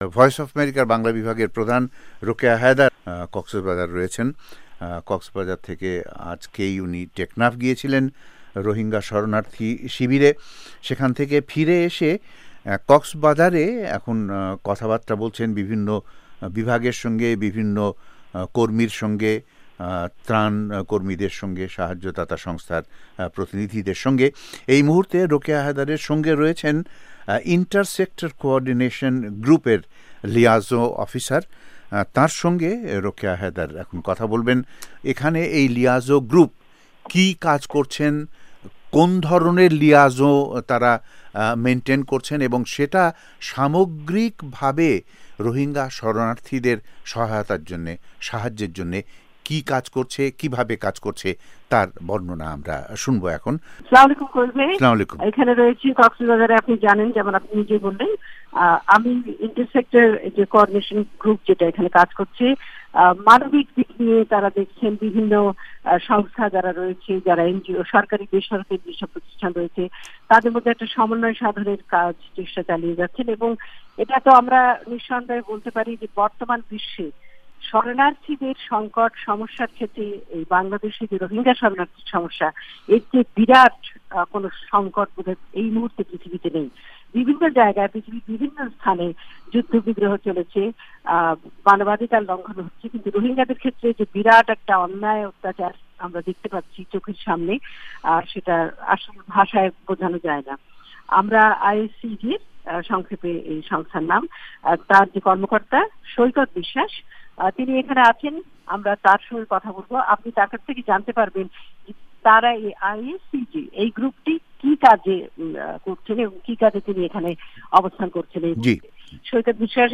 কুতুপালং রোহিঙ্গা শরনার্থী শিবিরের ত্রাণ তৎপরতা নিয়ে আলোচনা